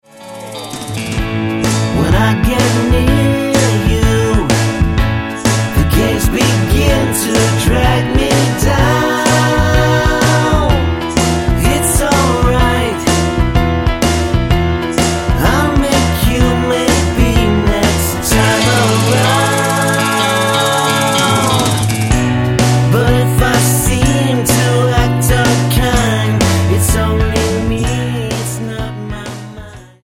--> MP3 Demo abspielen...
Tonart:A Multifile (kein Sofortdownload.
Die besten Playbacks Instrumentals und Karaoke Versionen .